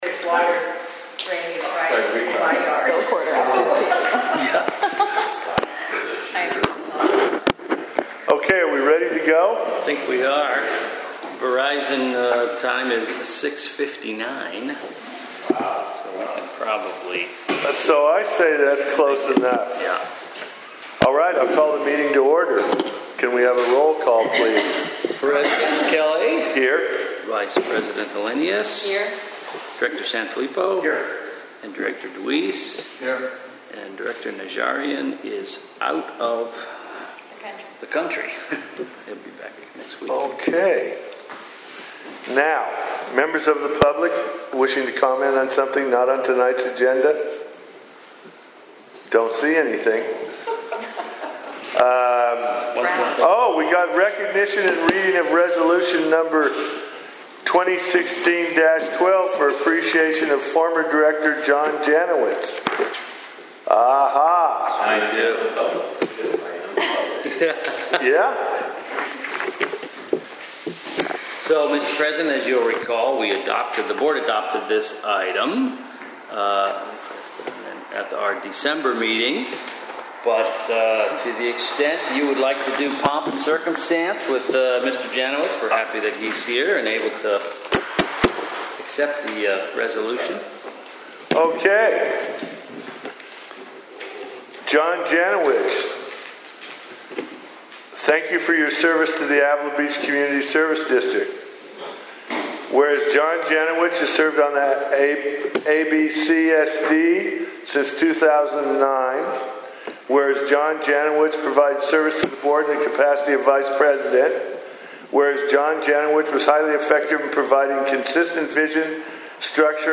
The District’s Board Meetings are held at 1:00 p.m. on the second Tuesday of each month in the District Office Board Room at 100 San Luis Street in…
Board of Directors Meeting